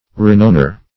Renowner \Re*nown"er\ (-?r), n. One who gives renown.